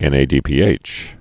(ĕnā-dēpē-āch)